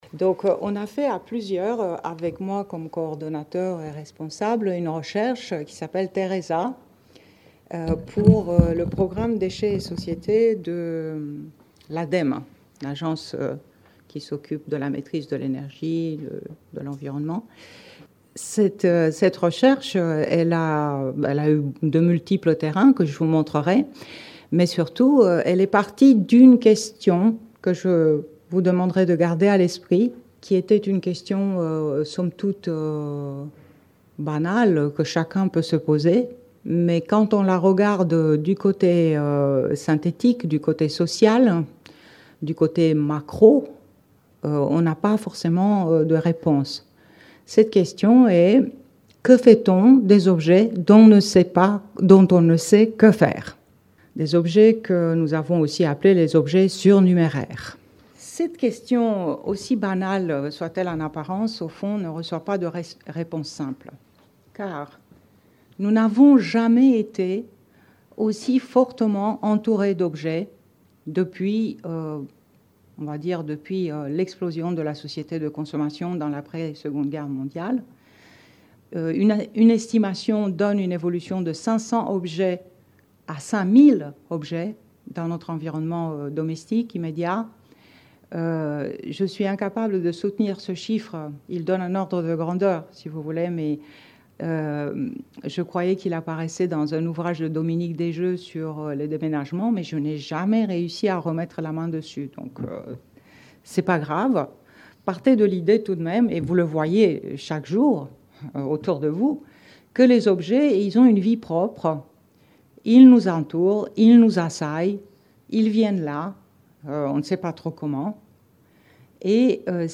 En procédant à des observations, des récits de vie centrés sur les objets et une expérimentation de théâtre forum, les chercheurs ont mis en évidence des itinéraires d’objets lorsqu’ils ont rempli leur première fonction, lors donc de leur « seconde vie ». Quatre types de rapports aux objets en sont ressortis dont les profils seront abordés dans cette conférence.